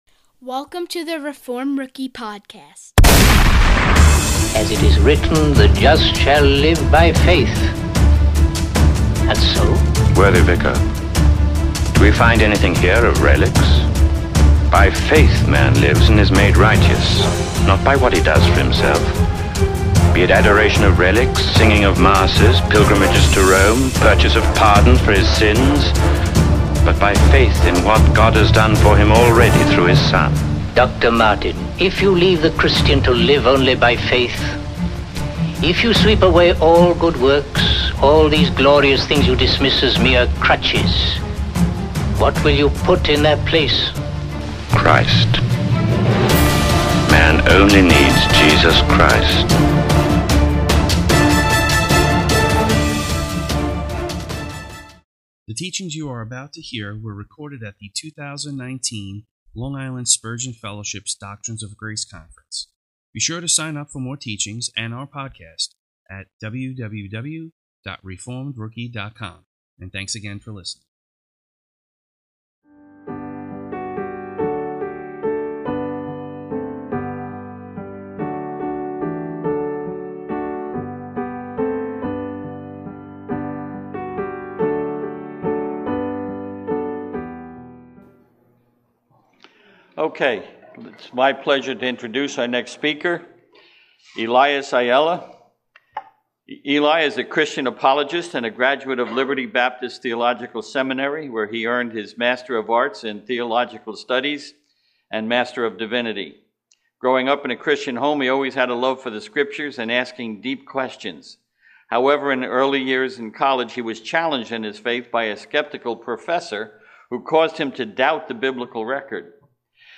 Recorded at the LI Spurgeon Fellowship: Doctrines of Grace Conference 2019